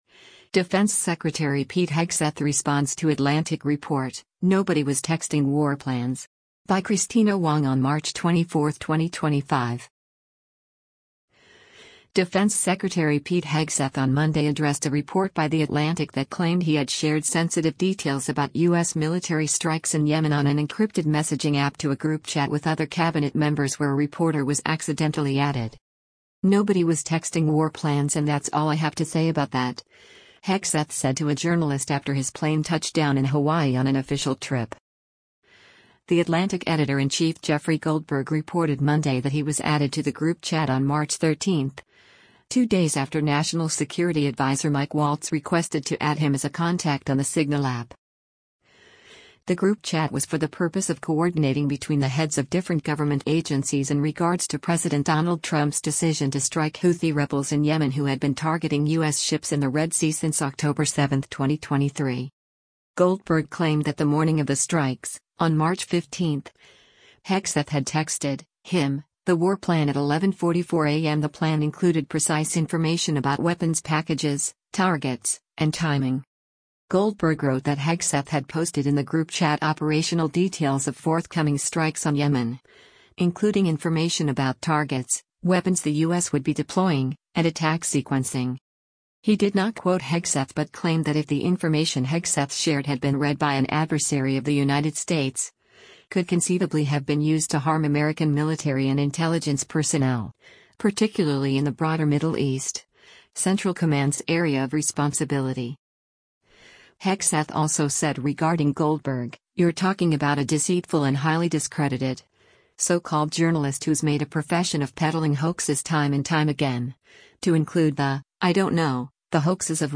“Nobody was texting war plans and that’s all I have to say about that,” Hegseth said to a journalist after his plane touched down in Hawaii on an official trip.